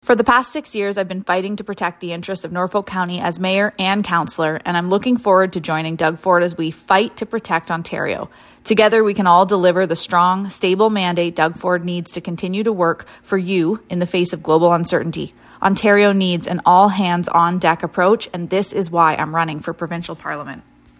We are reaching out to candidates to come in for an interview about why they want to serve as Haldimand-Norfolk’s Member of Provincial Parliament.